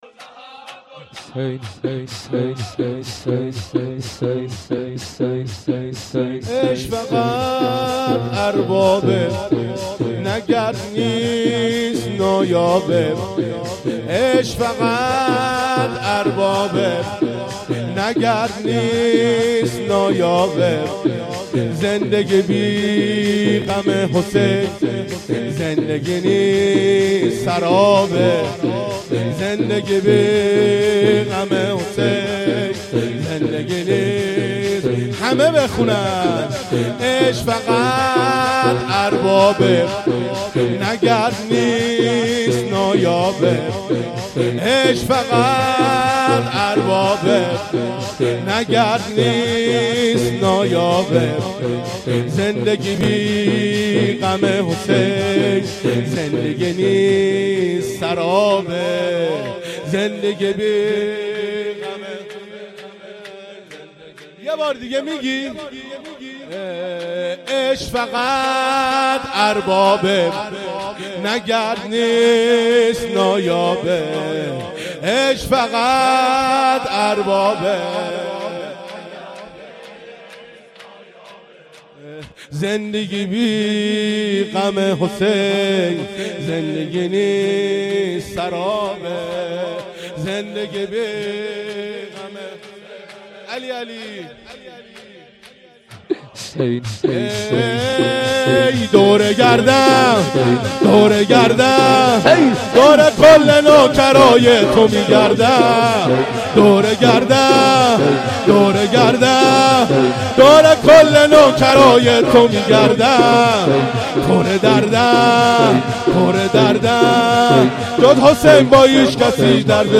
شب پنجم محرم 98